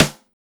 BEAT SD 03.WAV